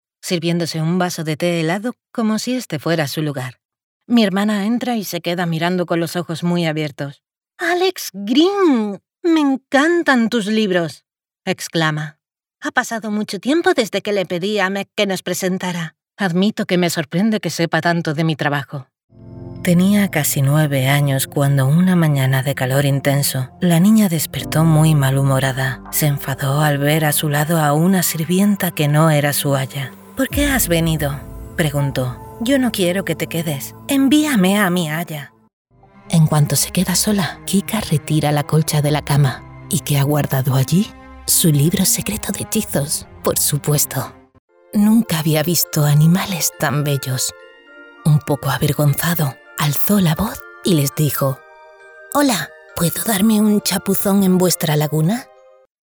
Soy una locutora espaĂ±ola con acento espaĂ±ol castellano neutro. Voz femenina comercial, publicitaria, natural, convincente, elegante, juvenil, adulta.
Sprechprobe: Sonstiges (Muttersprache):
I have my own professional soundproof studio.